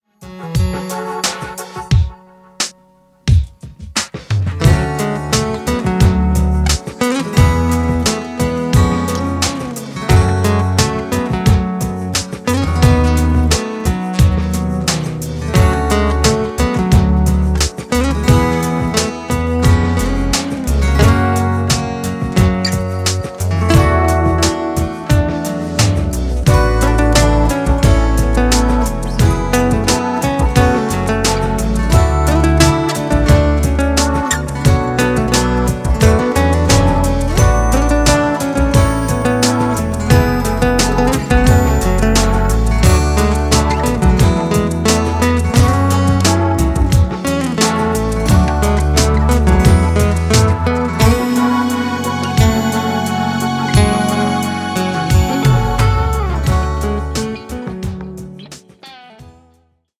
Instrumental
Dabei greift er nicht nur auf die E-Gitarre zurück
akustischen Gitarre